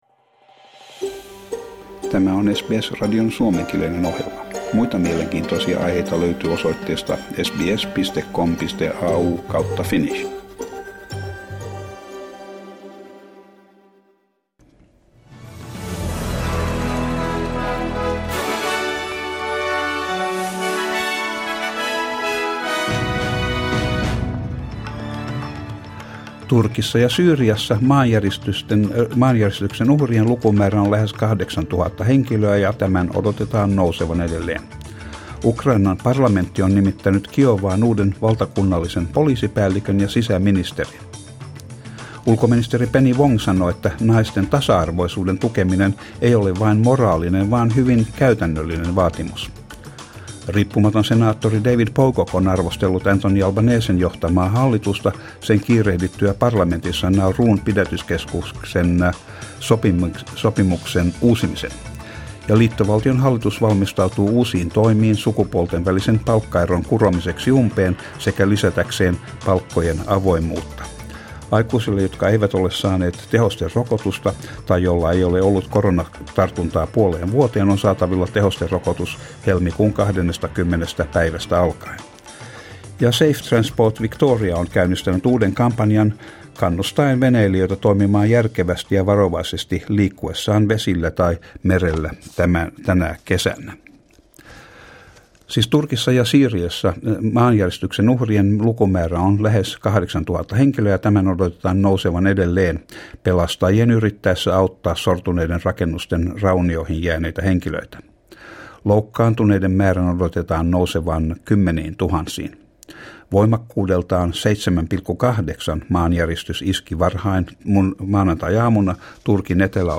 Suomenkieliset uutiset Source: SBS